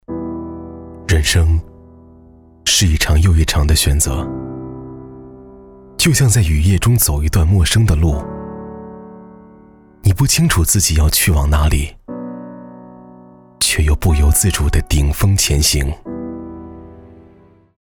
男国373_其他_旁白_走心高端质感.mp3